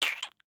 salamander-v1.ogg